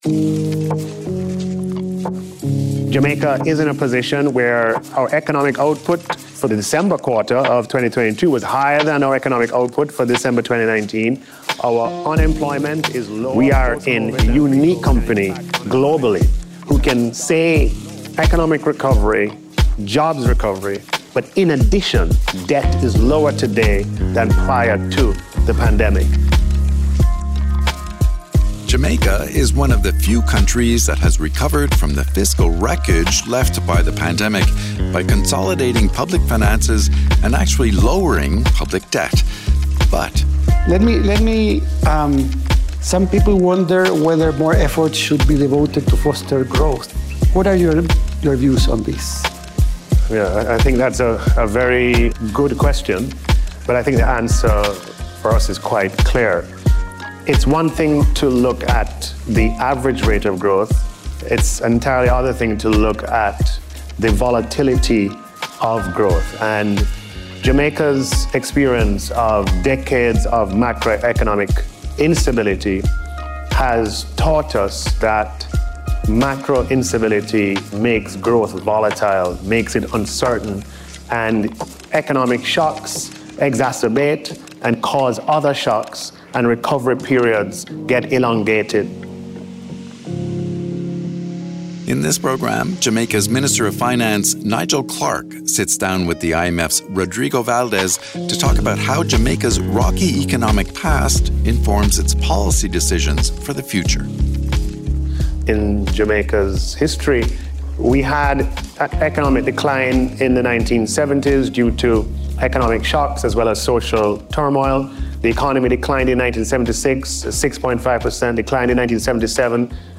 Nigel Clarke has been Jamaica’s Minister of Finance since 2018 and led its economy through the pandemic as well as devastating natural disasters caused by climate change. In this podcast, Clarke sits down with IMF Western Hemisphere Department head, Rodrigo Valdés, to discuss Jamaica’s strong track record of investing in institutions and prioritizing macroeconomic stability. The conversation took place as part of the IMF’s Governor Talks series held during the Annual Meetings in Marrakech.